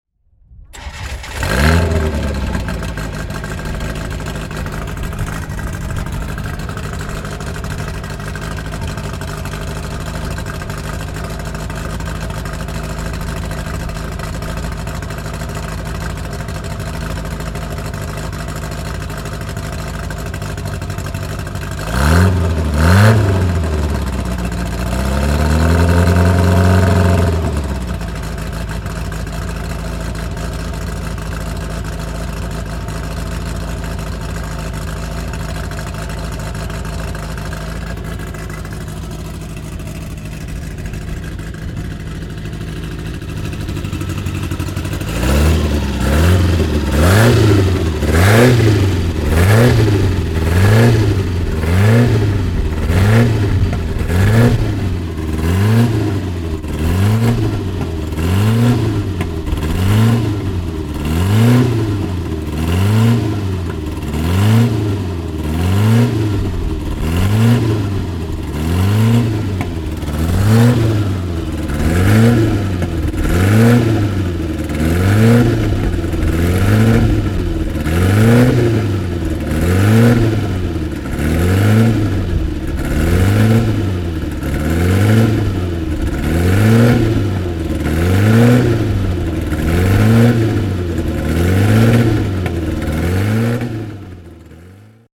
Motorsounds und Tonaufnahmen zu Abarth Fahrzeugen (zufällige Auswahl)
Fiat-Abarth 1000 TCR (1970) - Starten und Leerlauf der "Berlina Corsa"